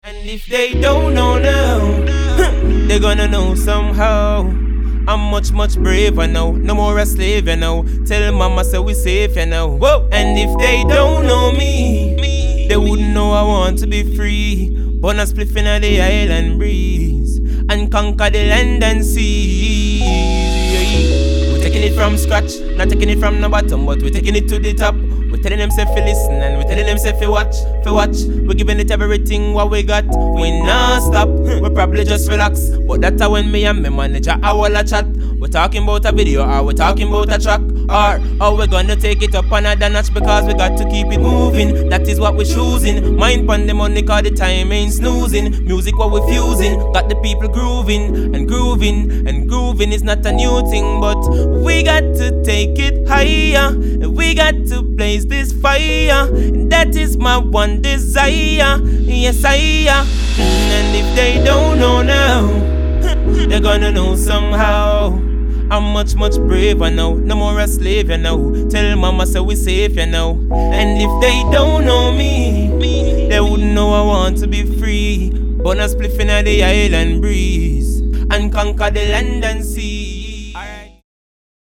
柔らかなローズの音色としっとりとしたベースをバックに歌い上げるなんとも印象深いナンバー！